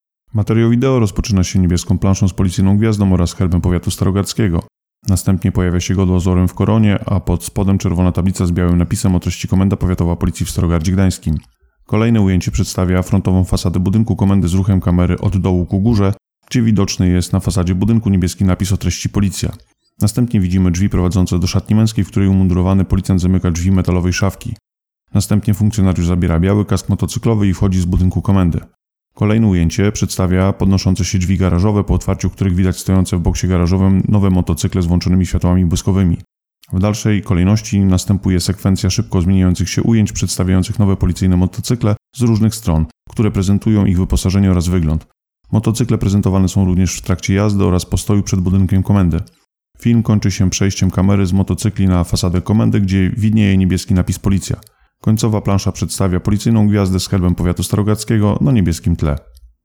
Nagranie audio Nowe motocykle starogardzkiej drogówki - audiodeskrypcja.